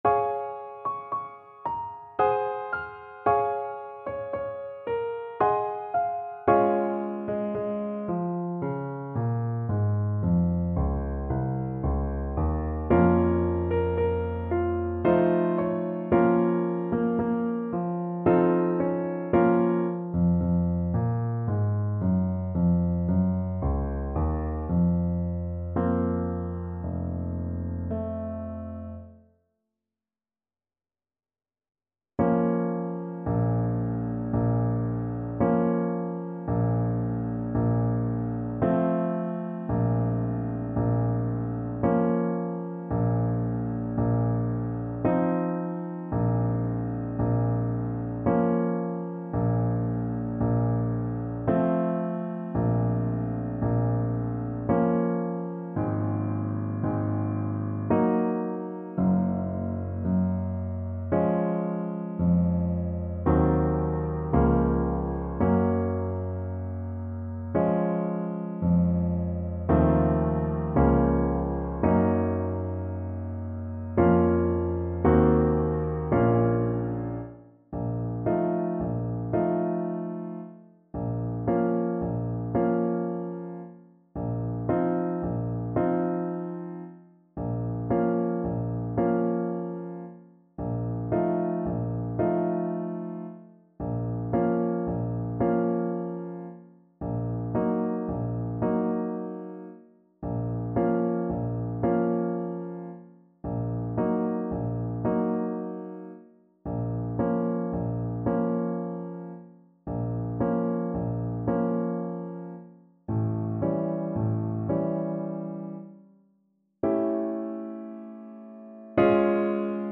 Play (or use space bar on your keyboard) Pause Music Playalong - Piano Accompaniment Playalong Band Accompaniment not yet available transpose reset tempo print settings full screen
Db major (Sounding Pitch) Eb major (Clarinet in Bb) (View more Db major Music for Clarinet )
~ = 56 Andante
3/4 (View more 3/4 Music)